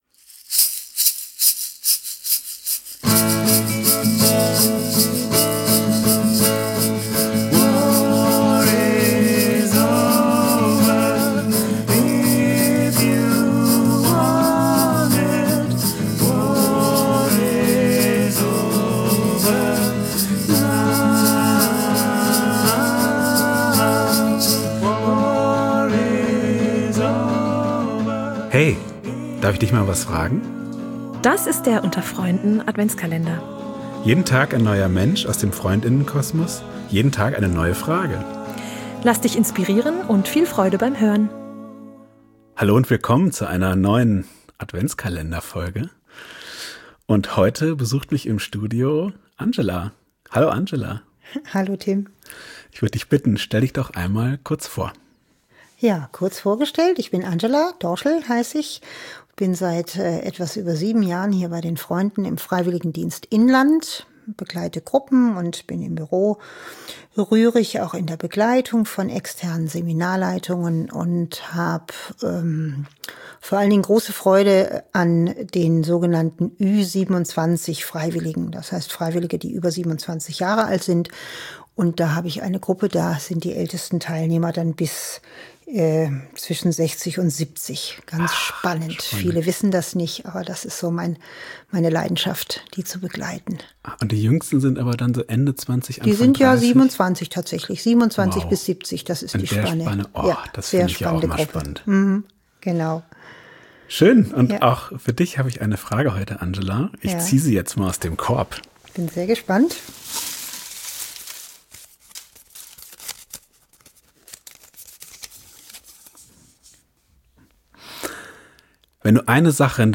In unserem Adventskalender zum Hören wird jeden Tag eine andere Person aus dem Freundinnen-Kosmos zu einer persönlich-philosophischen Frage kurz interviewt und darf spontan dazu antworten.